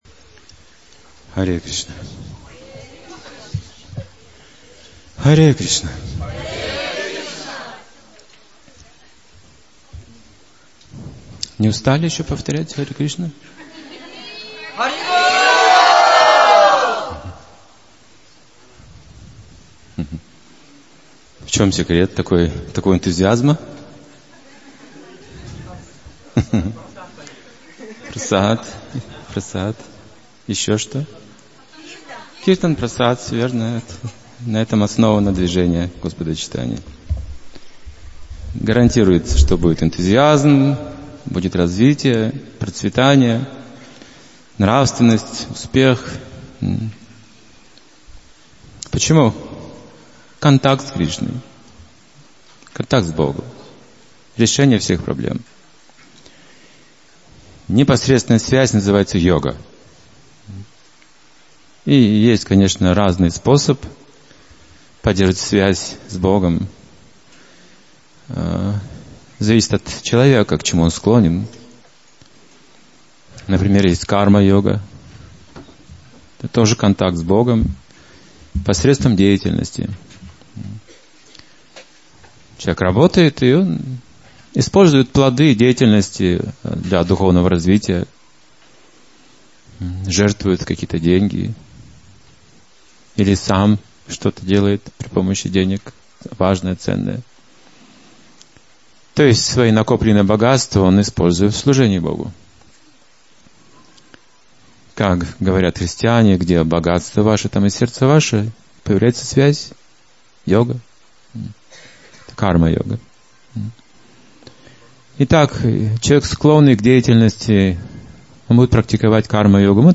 Лекция на Киртан меле (2016, Алматы)